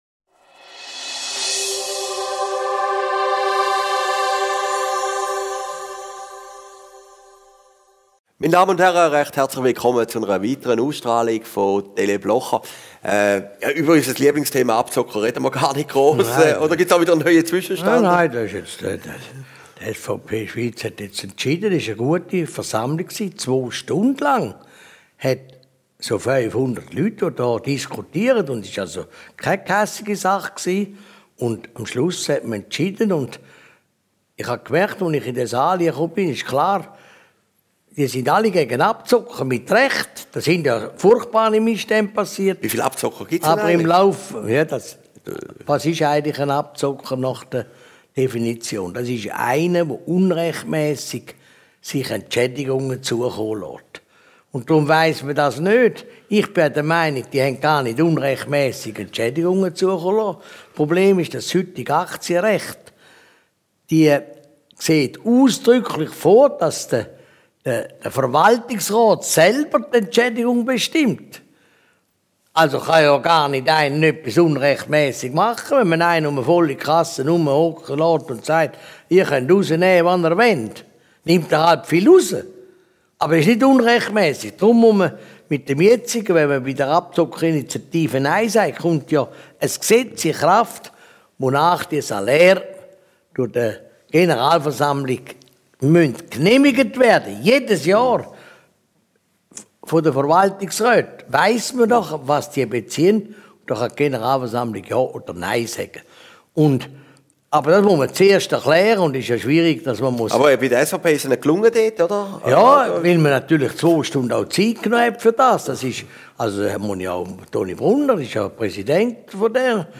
Video downloadenMP3 downloadenChristoph Blocher über den “Fall Brüderle”, die Abzockerschlacht und die Bündner Olympiapläne Aufgezeichnet in Herrliberg, 01.